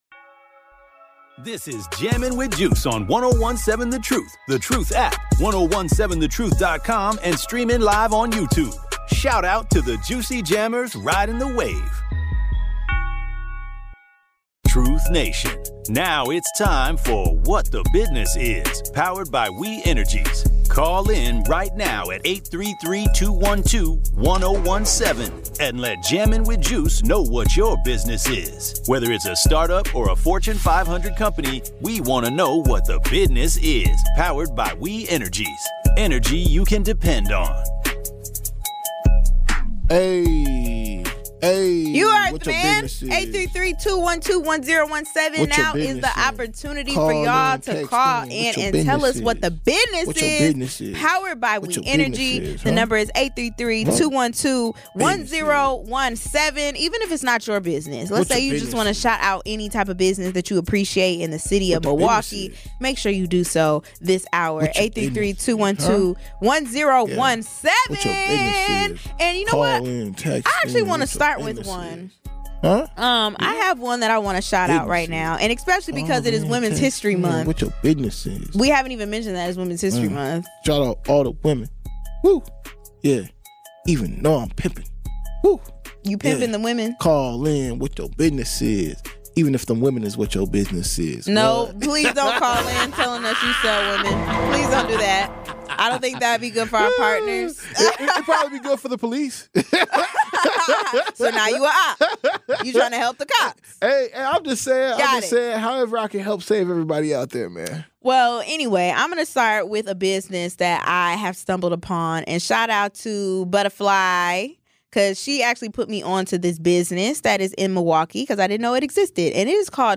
First, it’s What the Business, where Black-owned businesses have the spotlight! Call in to shout out your business, tell us what makes it special, and share your journey.
Whether you're calling to share your weekend plans or recap the fun you had, we’re all about connecting and celebrating what makes our weeks memorable.